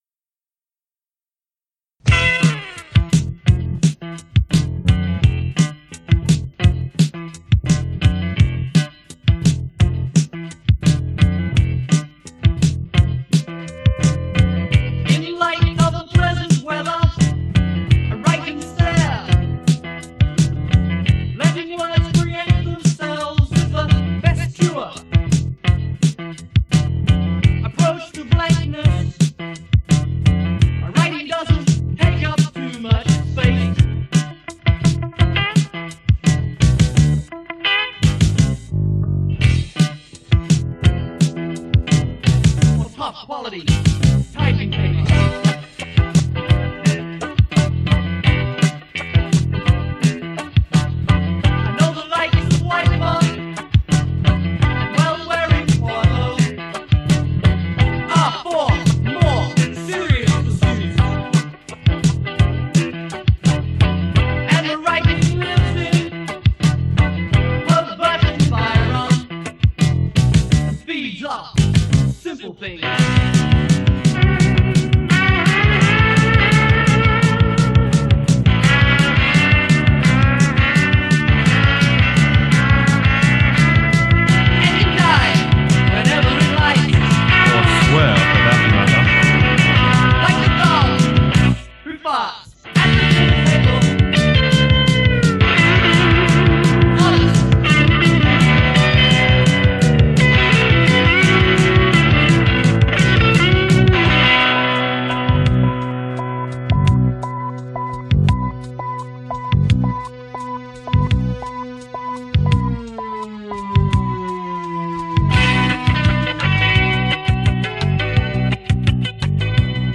lead vocals/bass/keyboards